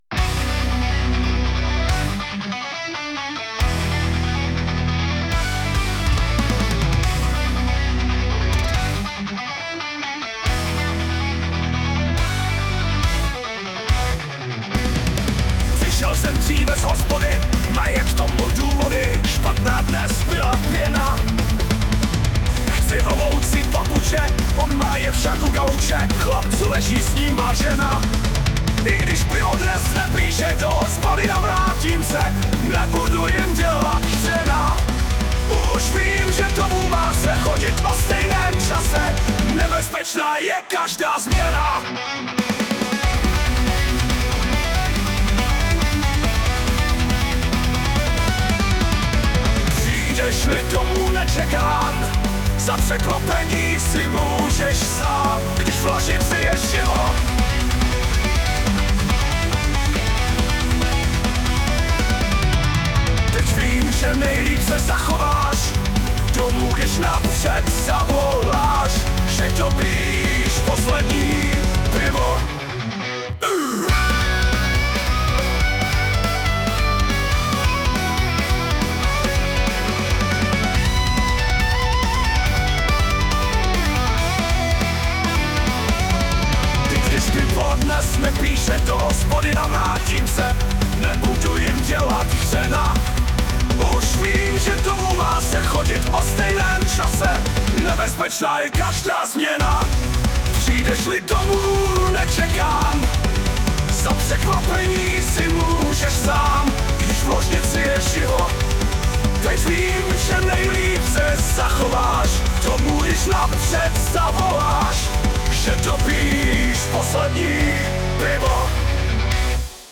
hudba, zpěv, obrázek: AI